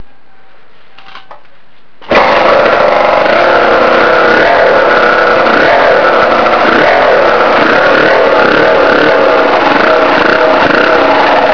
洗浄したタンクを仮に取付け、ちょっとエンジンかけてみますか〜〜！
サイレンサーは、まだ付いていないんですけど？
エンジンをかけてみて下さい
注）正真正銘の一発目です
エンジンも無事始動！